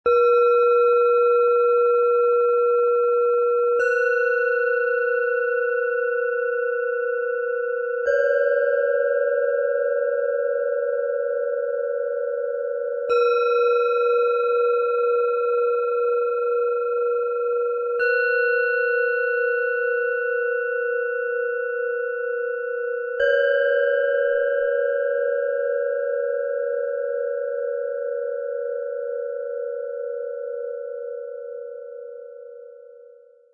Getragene Ruhe - Erdung, Stabilität, Klarheit - Set aus 3 Klangschalen, Ø 10,6 - 11 cm, 0,8 kg
Mit Geduld entfaltet sie ihren warmen, klaren Klang und zeigt, dass kleine Missklänge liebevoll ausgeglichen werden können.
Im Sound-Player - Jetzt reinhören können Sie den Original-Ton genau dieser Schalen des Sets Getragene Ruhe anhören. Lauschen Sie der tiefen Erdung, der stabilen Mitte und der sanften Entfaltung der kleinsten Schale.
Der kostenlose Klöppel, der mitgeliefert wird, erzeugt einen warmen und angenehmen Klang.
HerstellungIn Handarbeit getrieben
MaterialBronze